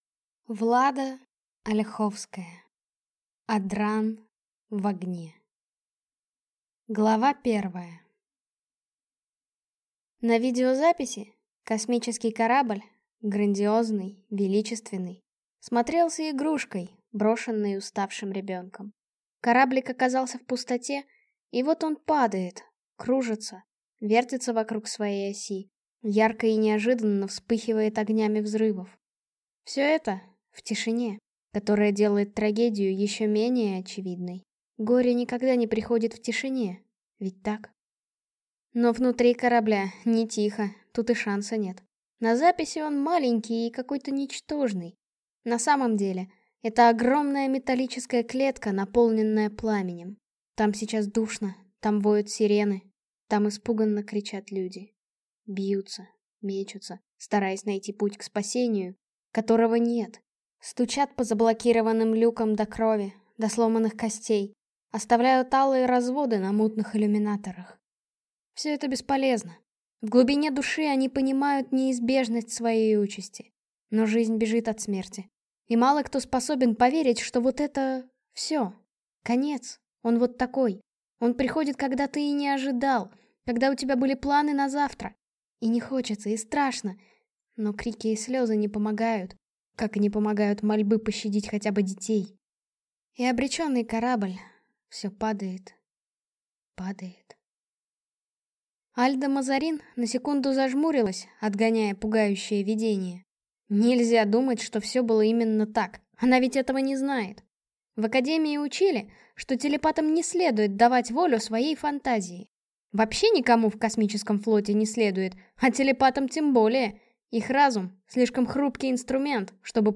Аудиокнига Адран в огне | Библиотека аудиокниг